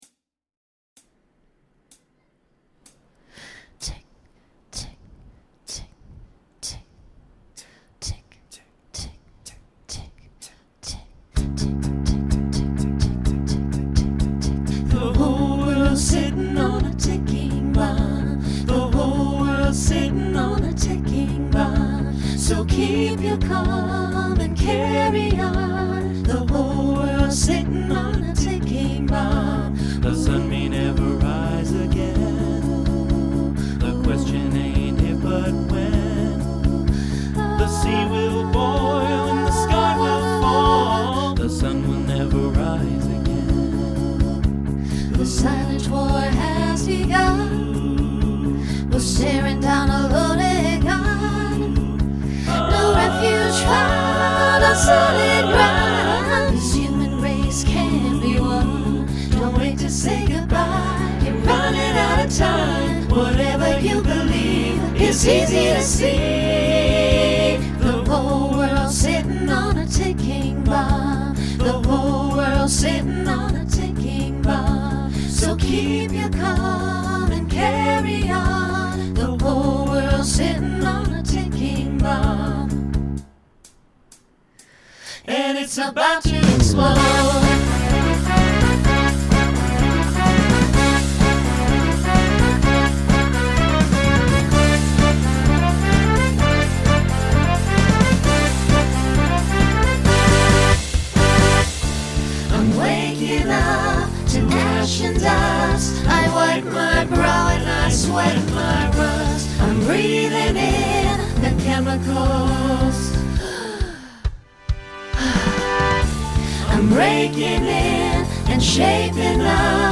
Genre Rock Instrumental combo
Mid-tempo , Story/Theme Voicing SATB